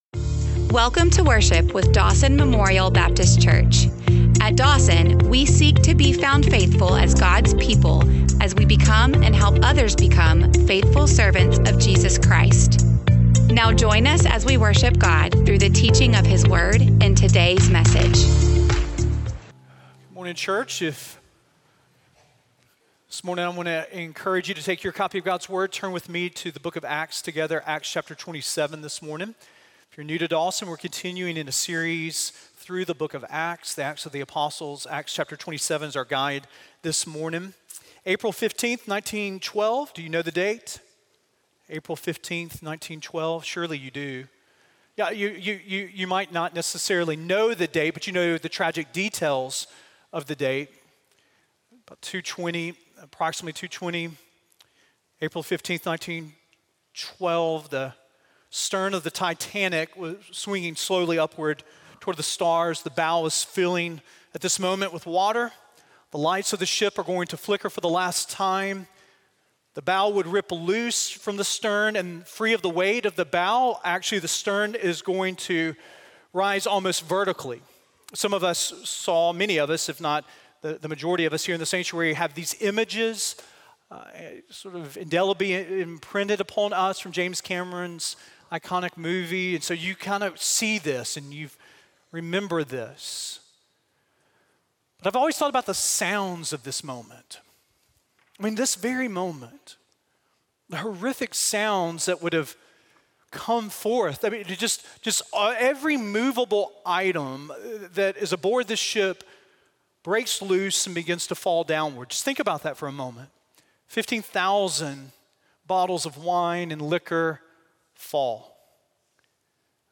Sermon1117audio.mp3